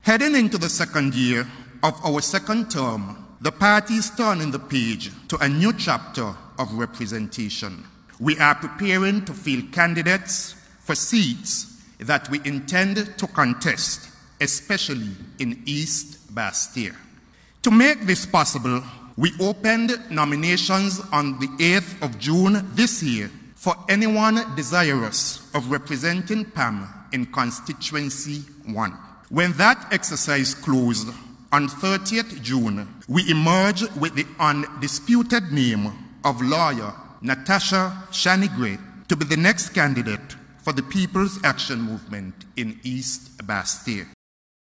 During a press conference at Royal St. Kitts Hotel, in St. Kitts, on Monday, Oct. 18th, Political Leader of the Party, Deputy Prime Minister, Shawn Richards, announced the individual.
PAM’s Political Leader, Deputy Prime Minister, Shawn Richards.